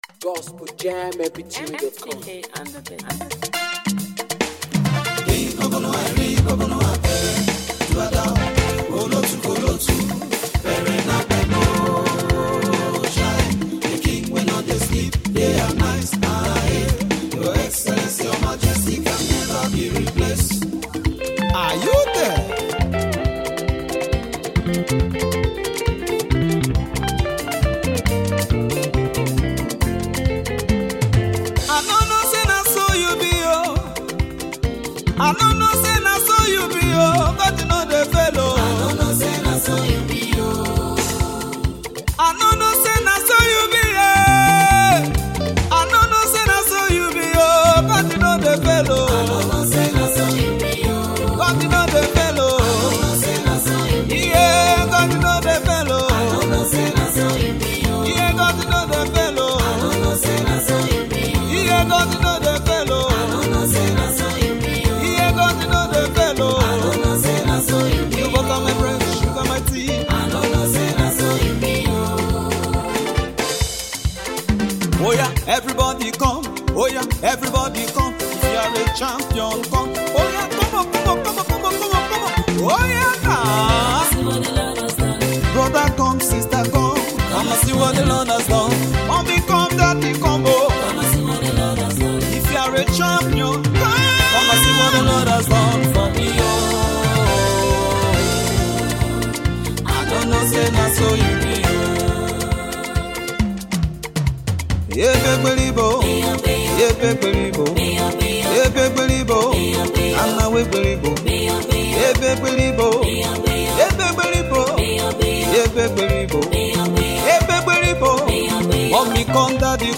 Afro beatmusic
Gospel Music
high pitch pattern of singing